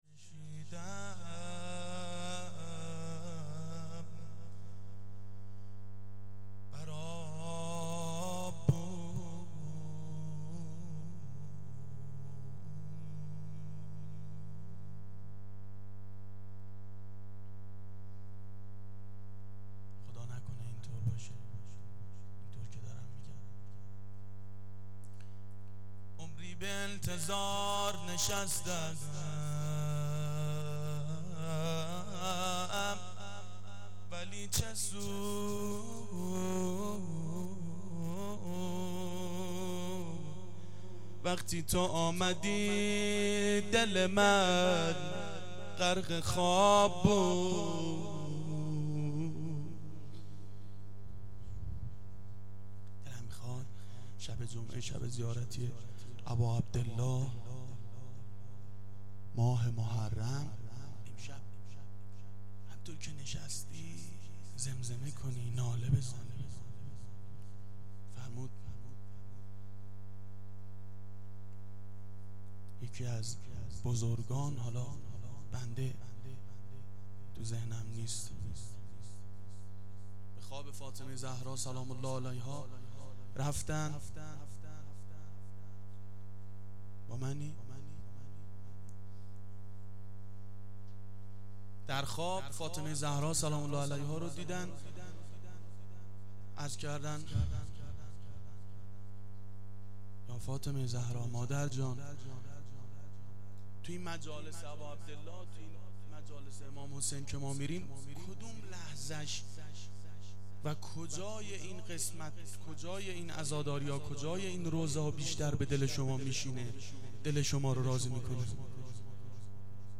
روضه
شب سوم محرم 89 گلزار شهدای شهر اژیه